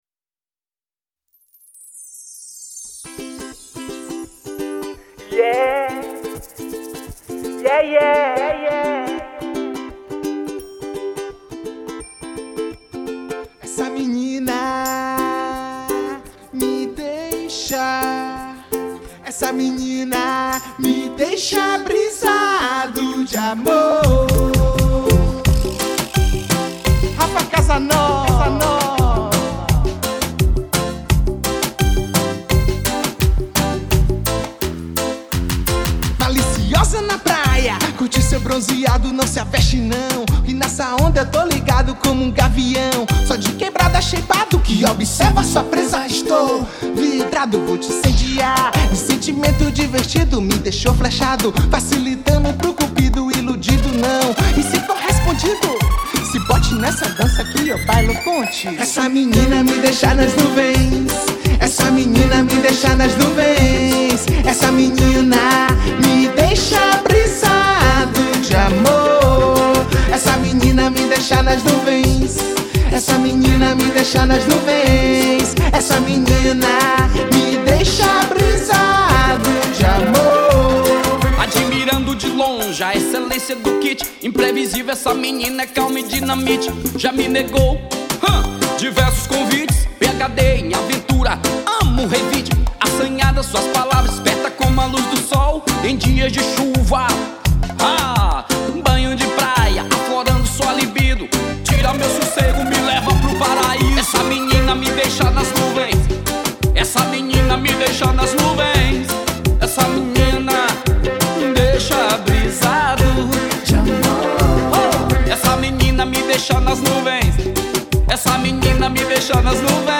EstiloReggaeton